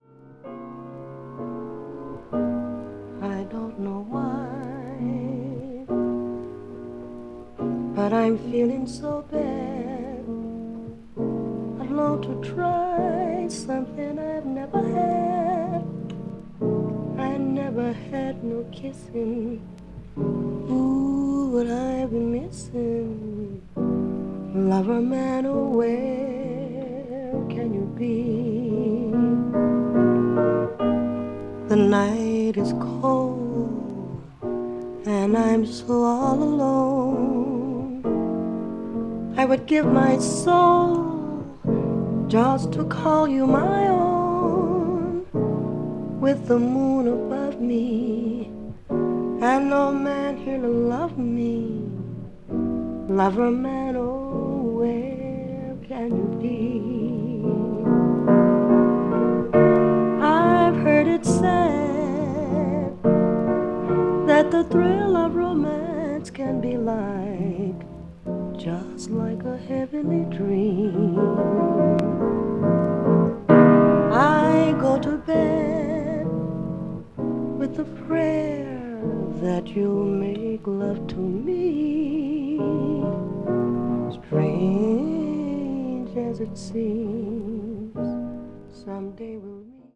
blues jazz   jazz standard   jazz vocal